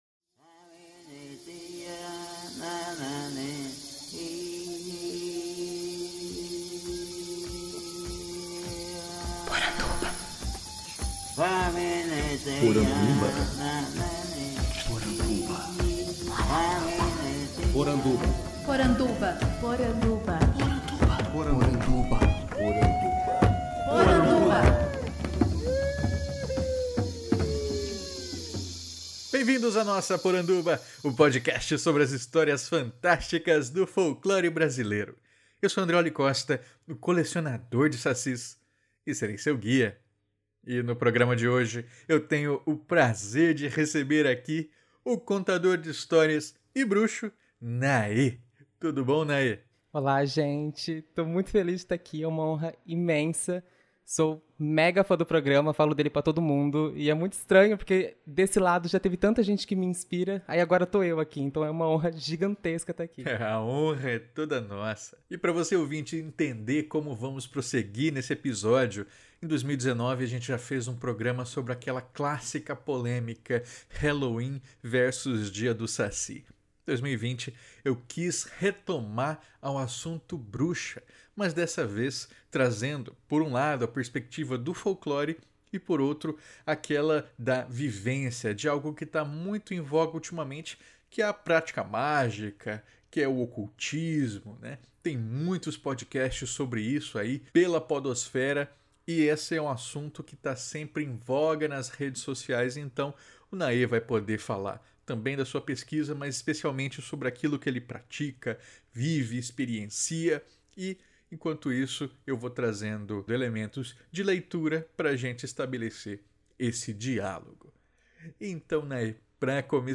Podcast que entrevista